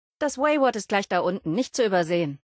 Kategorie:Fallout 76: Audiodialoge Du kannst diese Datei nicht überschreiben.